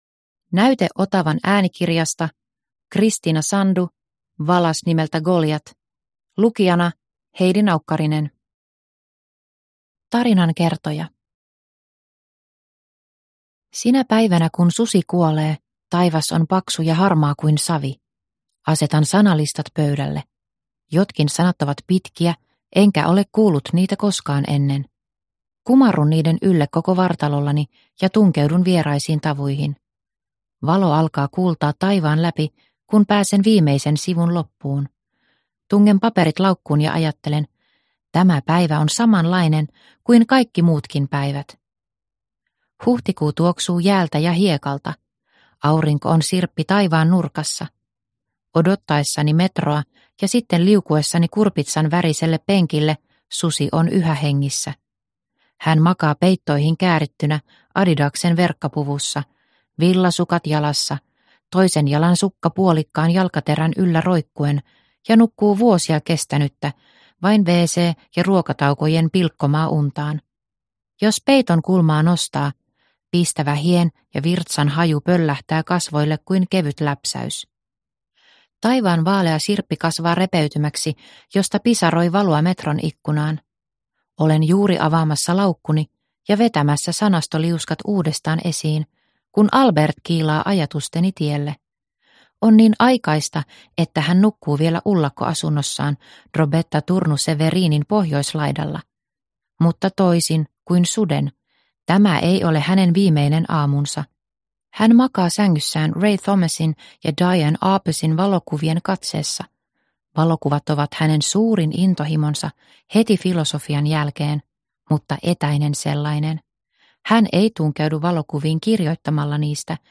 Valas nimeltä Goliat – Ljudbok – Laddas ner